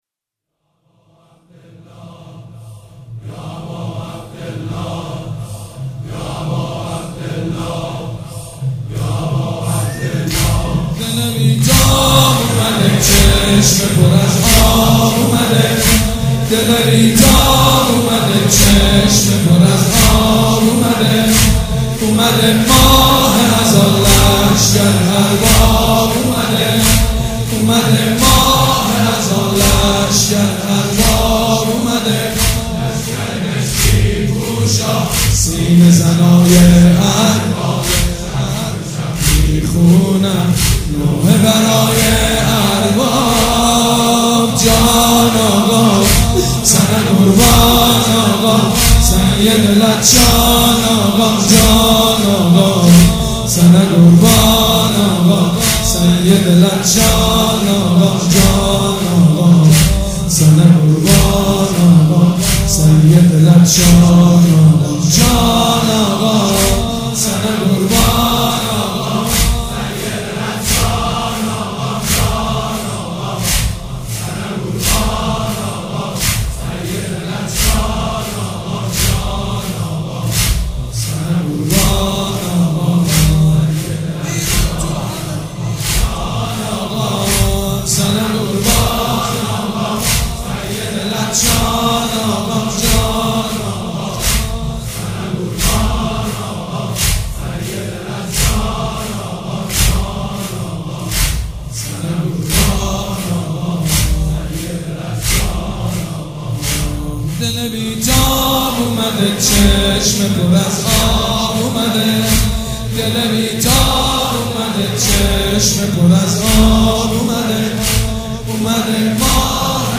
شب یازدهم محرم الحرام‌
مداح
حاج سید مجید بنی فاطمه
مراسم عزاداری شب شام غریبان